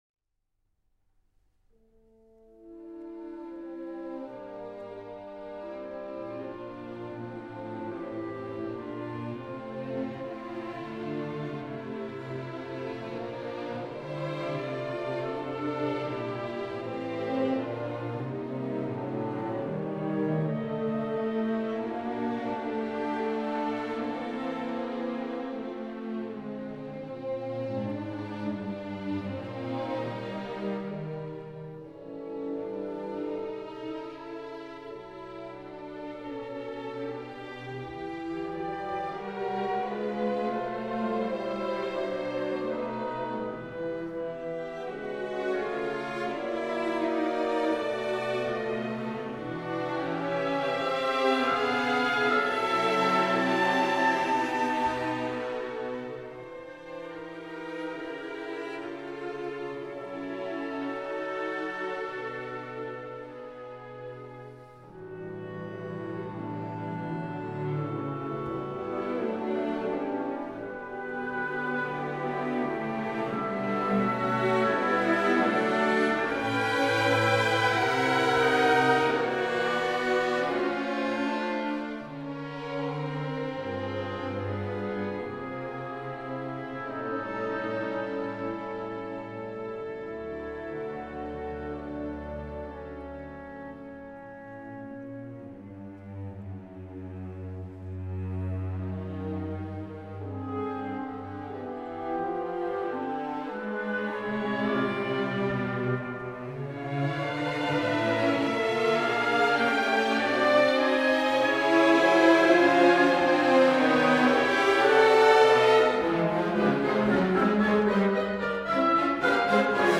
Schoenberg: Die Jakobsleiter: Friede auf Erden, Op. 13. Orchestral version